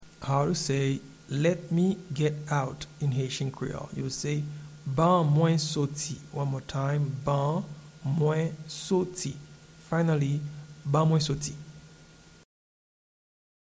Pronunciation and Transcript:
Let-me-get-out-in-Haitian-Creole-Ban-mwen-soti.mp3